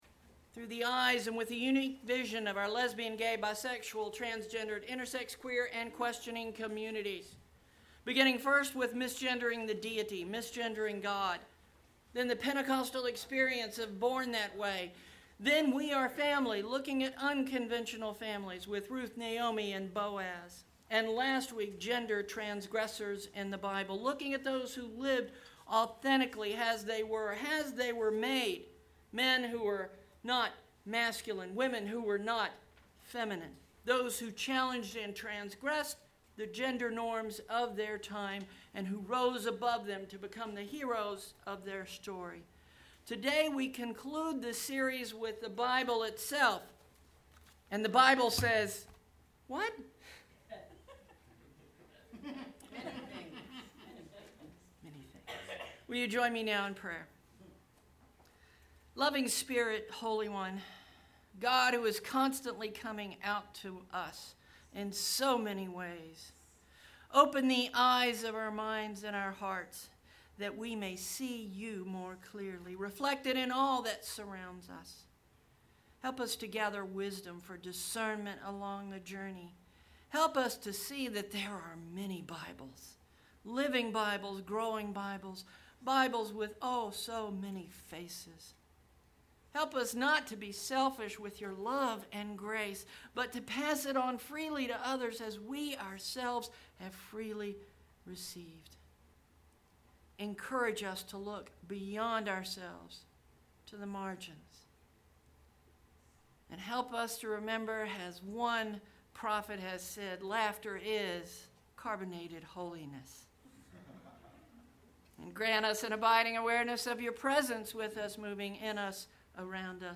Too many people spend too much time railing against what they believe is wrong instead of doing what they know is right. Ancient Readings: Micah 6:8; and Mark 12:28-31; and John 13:34-35 Contemporary Reading: Adapted from The Collected Sermons of Fred B. Craddock Sunday morning worship …